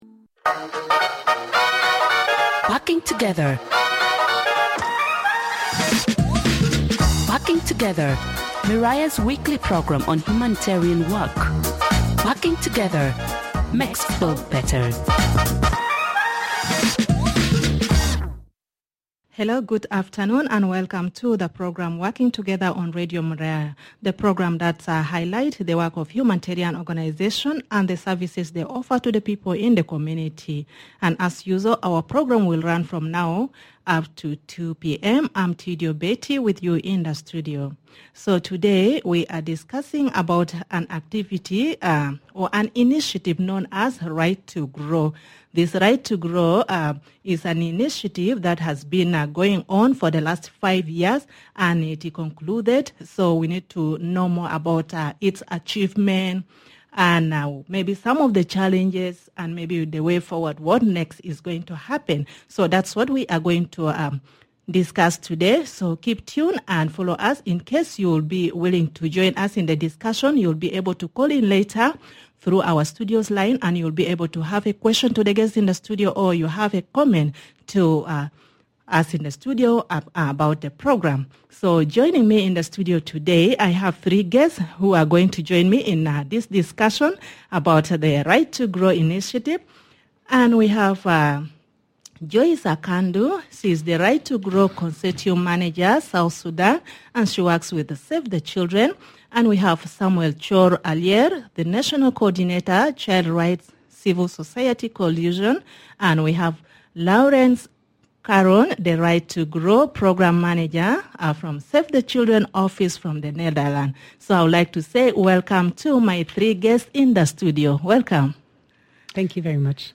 Guests in this discussion include